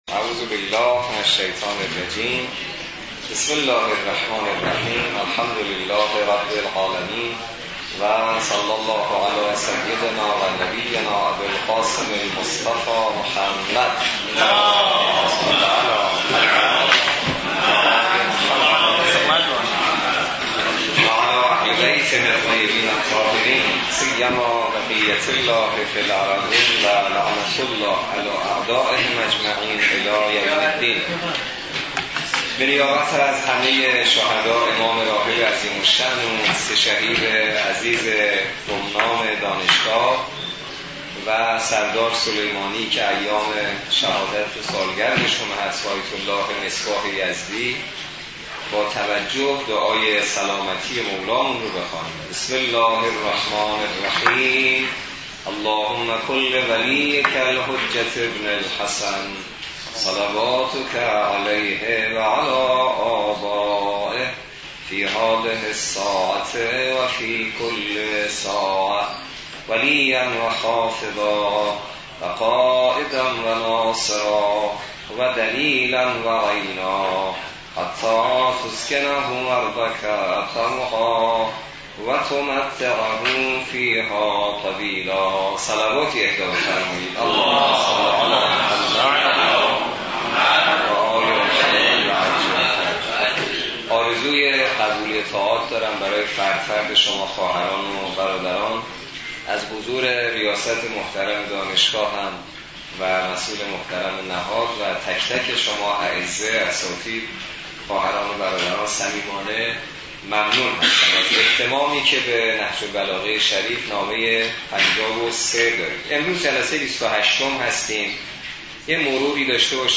برگزاری بیست و هشتمین جلسه مباحثه نامه ۵۳ نهج البلاغه توسط نماینده محترم ولی فقیه و در دانشگاه کاشان
بیست و هشتمین جلسه مباحثه نامه ۵۳ نهج البلاغه توسط حجت‌الاسلام والمسلمین حسینی نماینده محترم ولی فقیه و امام جمعه کاشان در دانشگاه کاشان برگزار گردید.